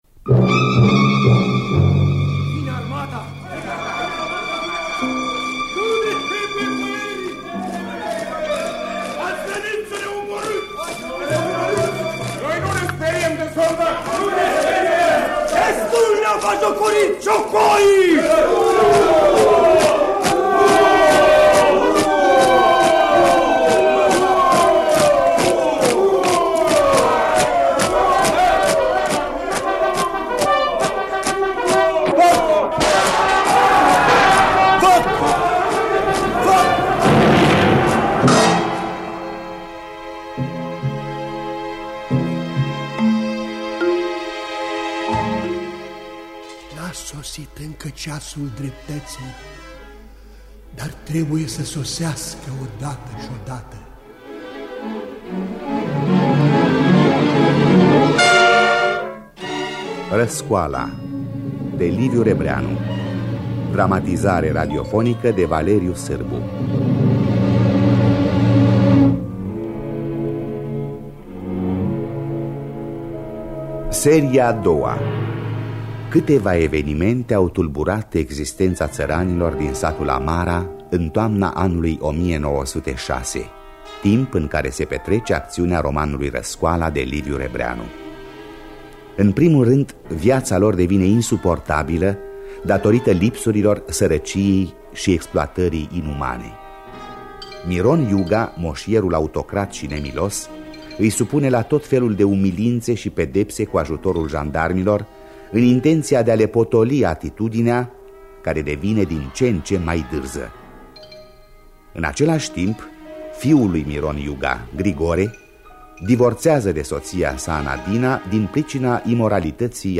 Partea a II-a. Dramatizarea radiofonică de Valeriu Sârbu.